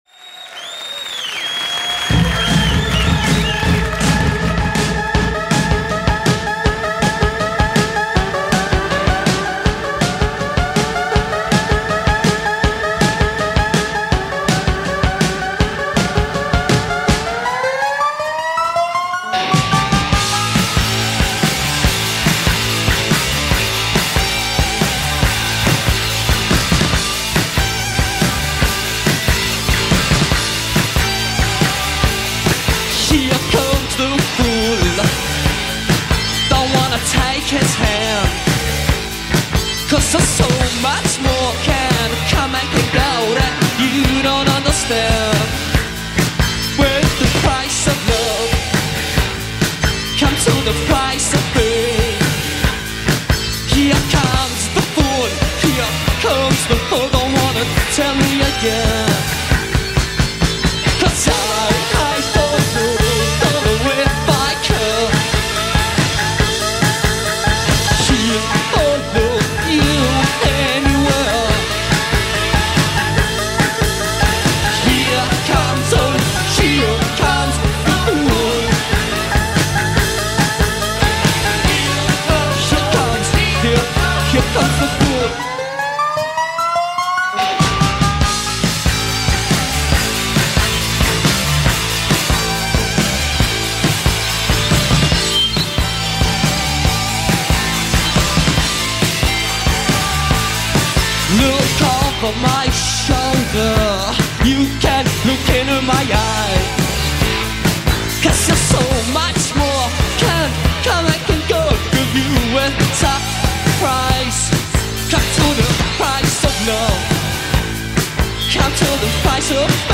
New Wave Concert Edition
in concert at The Paris Theatre, London